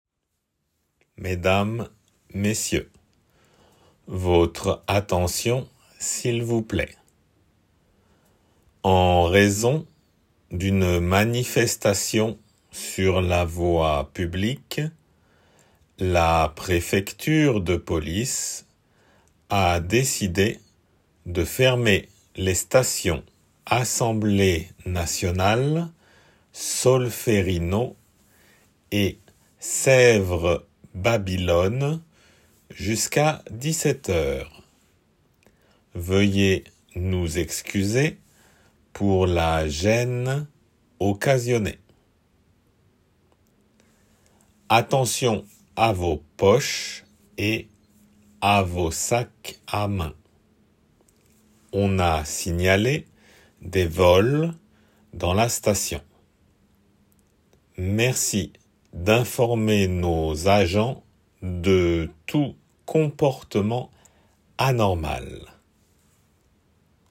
C’est une annonce de fermeture de stations de métro sur le parcours d’une manifestation.